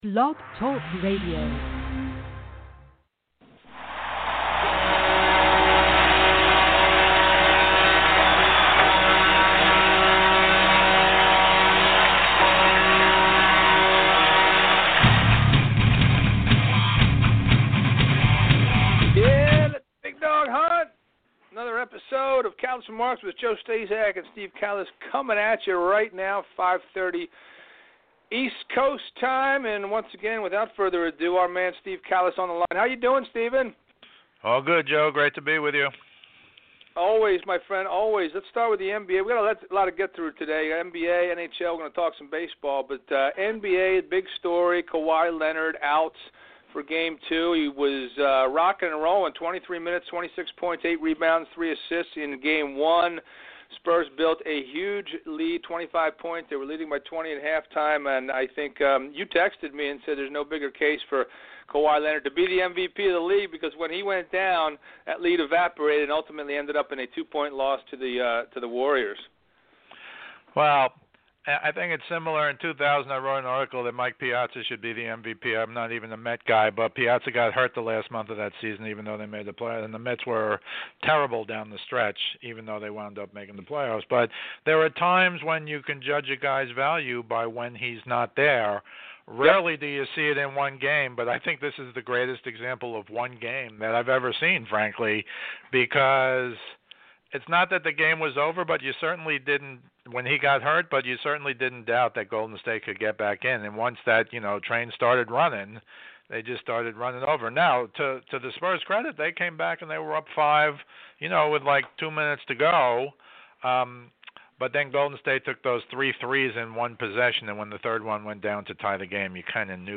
call in show. NBA and NHL playoffs, Draft Lottery and MLB